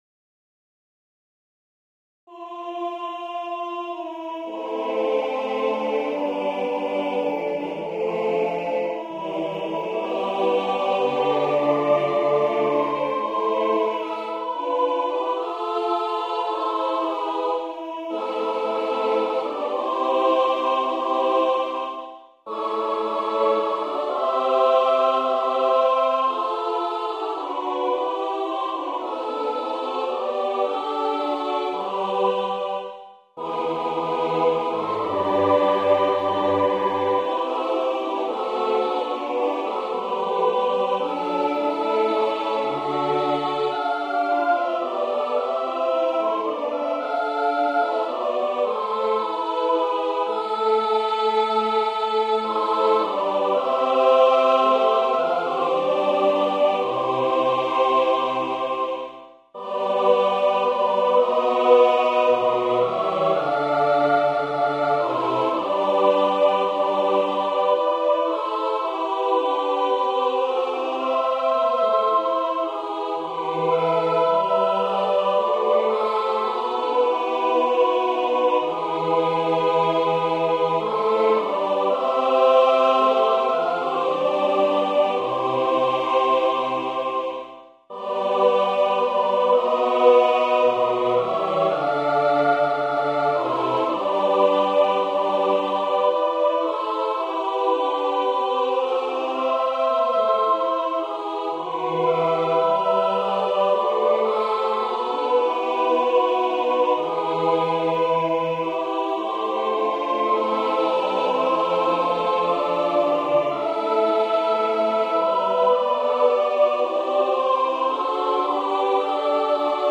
Number of voices: 5vv Voicing: SSATB Genre: Secular, Madrigal
Language: Italian Instruments: A cappella